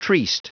Prononciation du mot triste en anglais (fichier audio)
Prononciation du mot : triste